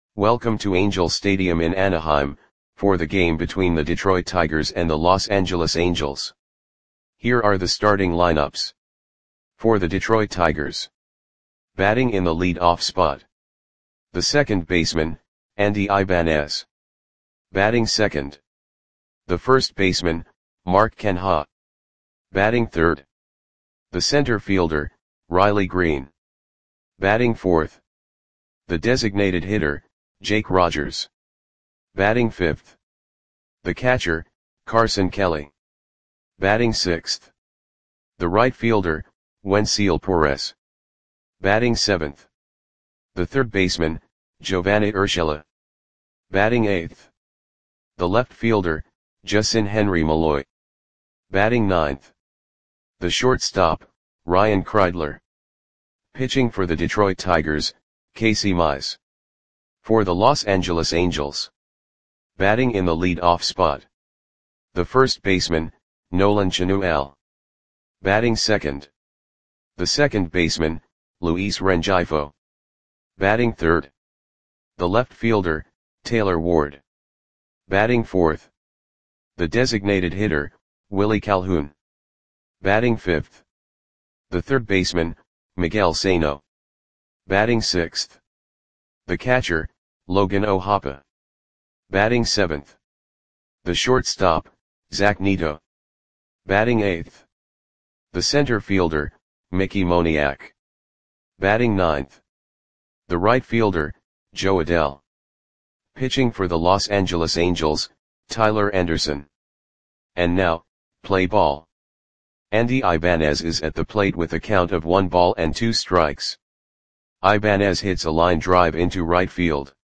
Audio Play-by-Play for Los Angeles Angels on June 30, 2024
Click the button below to listen to the audio play-by-play.